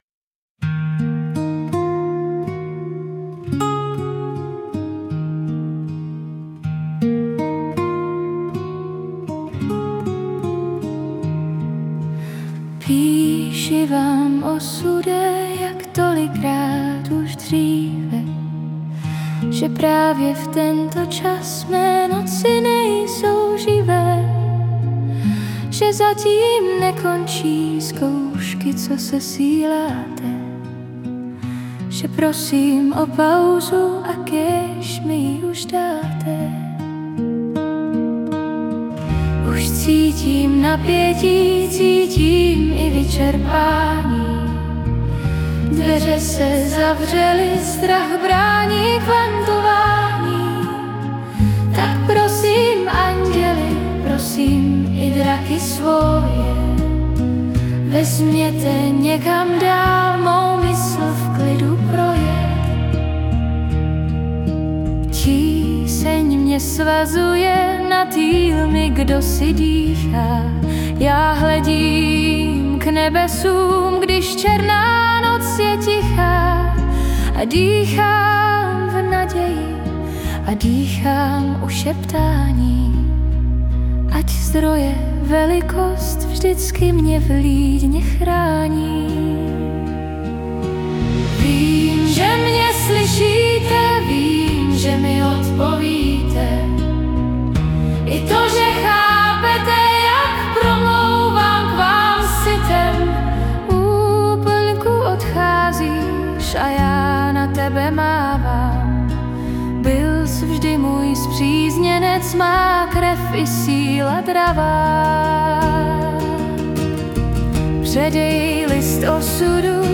v tom zpěvu to ale rychleji uteče, než když se to jenom četlo